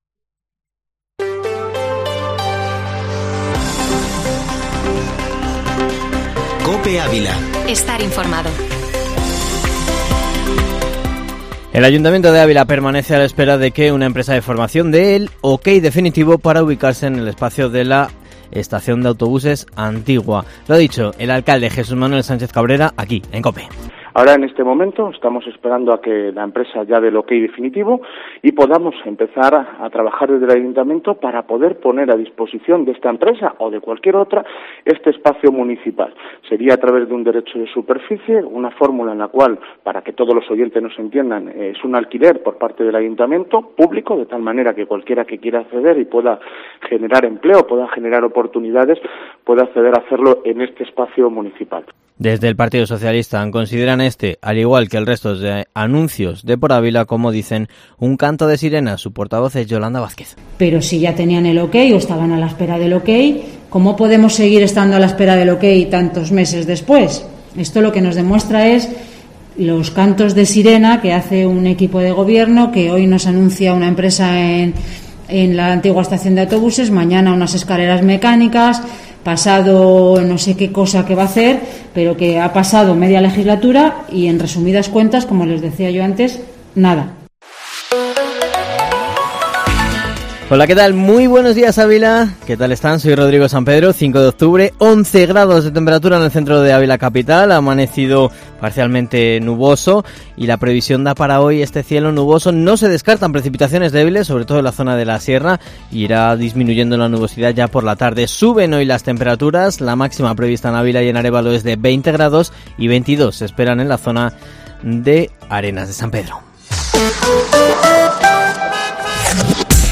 Informativo Matinal Herrera en COPE Ávila -5-oct